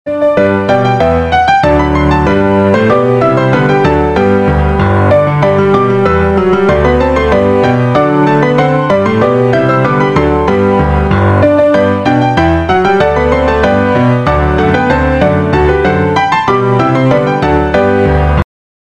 Instrumental 24 minutes The Twelve Days of Christmas